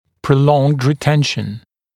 [prə’lɔŋd rɪ’tenʃn][прэ’лонд ри’тэншн]длительный период ретенции, длительная ретенция